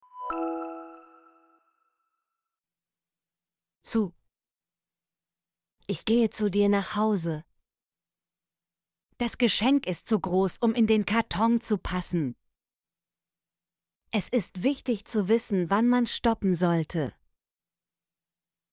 zu - Example Sentences & Pronunciation, German Frequency List